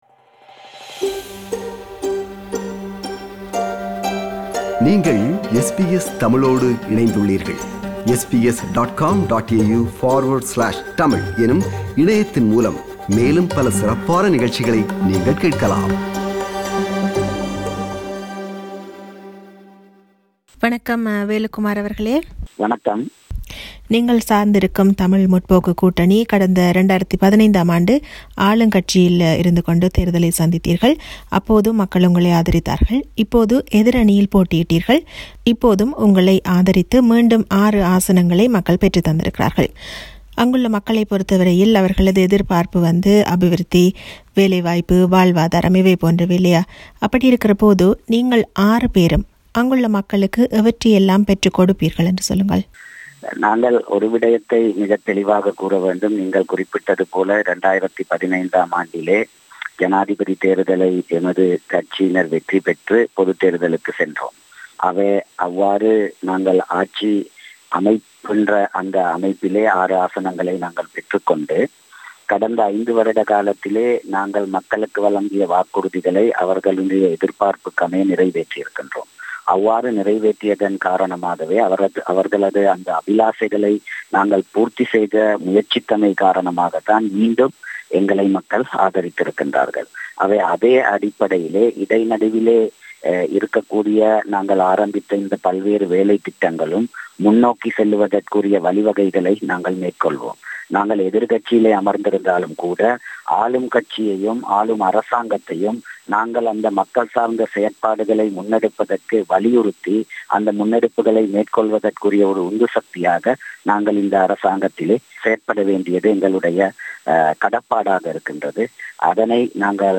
Tamil Progressive Alliance Representing Up- Country Tamils retains all 6 seats from Nuwara – Eliya, Colombo, Kandy and Badulla districts held by Tamil Progressive Alliance in the last parliament. This is an interview with Mr.M.Velu Kumar-MP, Tamil Progressive Alliance.